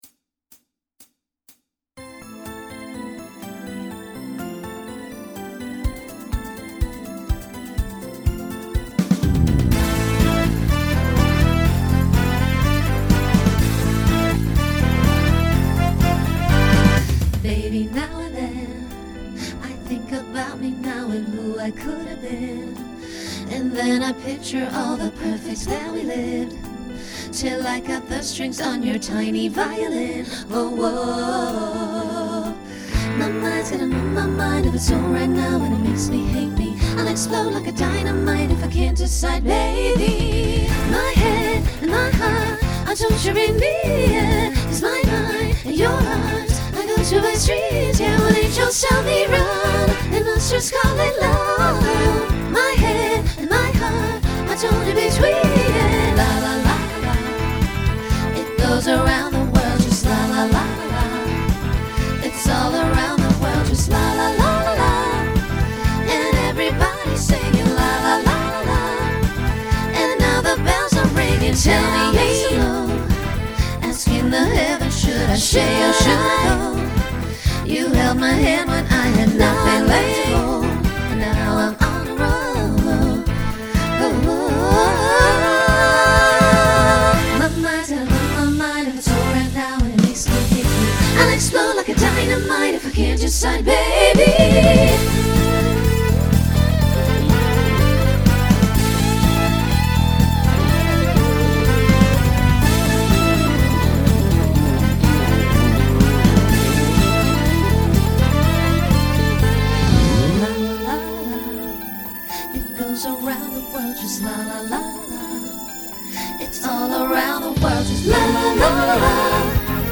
Optional SATB at the end.
Genre Pop/Dance
Transition Voicing SSA